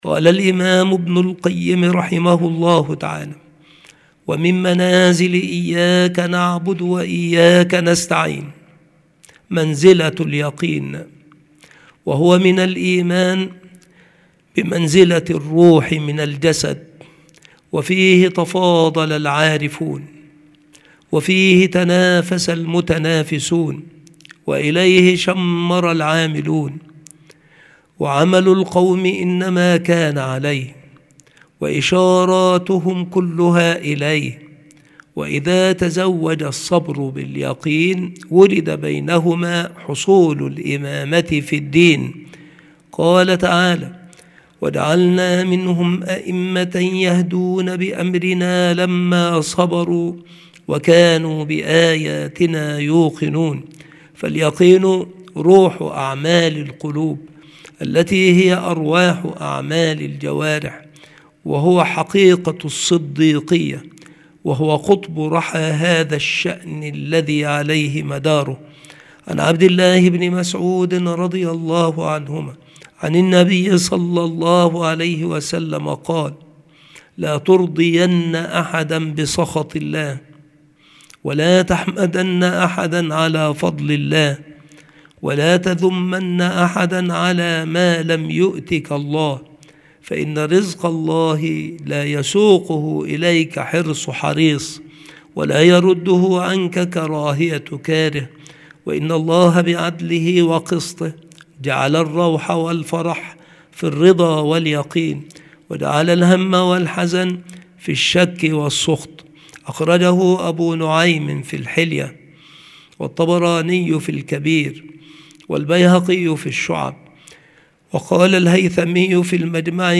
مكان إلقاء هذه المحاضرة المكتبة - سبك الأحد - أشمون - محافظة المنوفية - مصر عناصر المحاضرة : منزلة اليقين. صلة اليقين بالتوكل. أقوال السلف في اليقين. درجات اليقين عند صاحب المنازل. الفرق بين علم اليقين وعين اليقين.